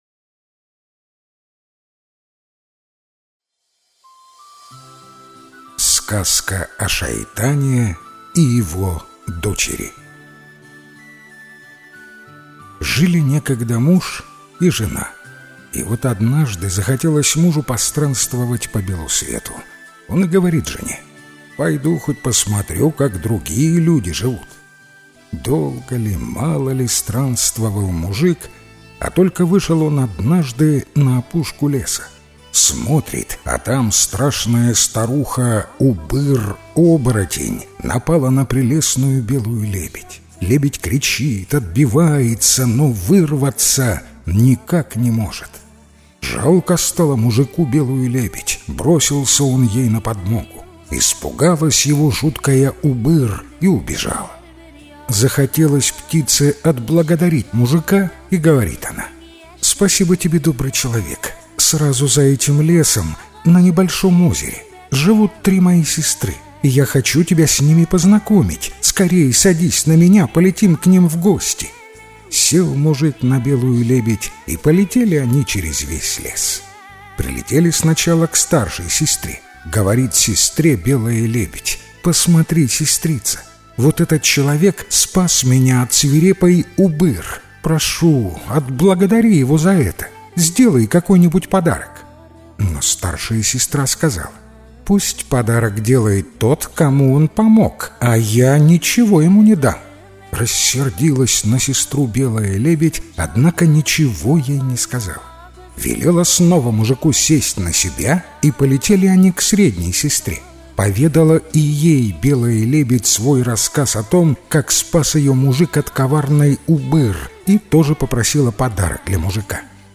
Сказка о шайтане и его дочери - татарская аудиосказка - слушать онлайн